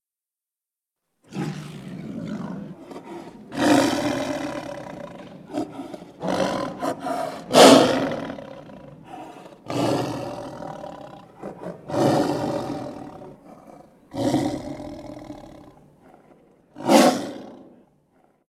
Efecto especial de felino